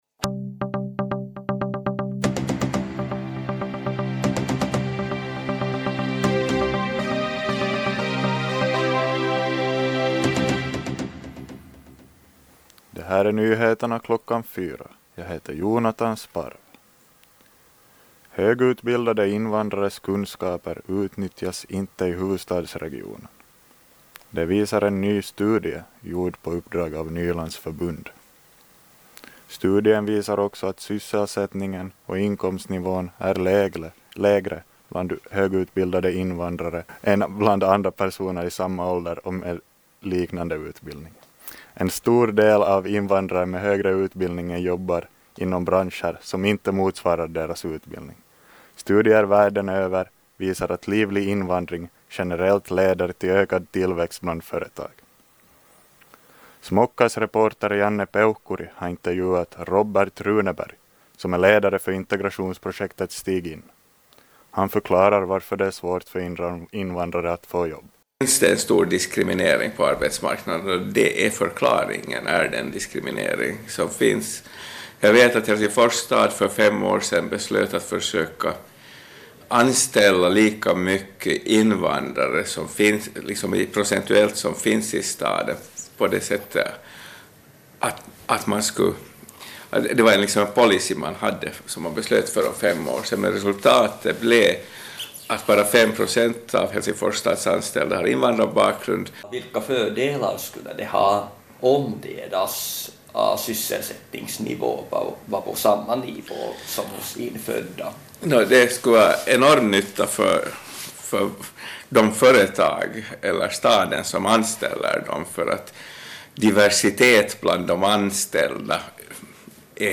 Radionyheter 16.4.2019 kl. 16 - Smocka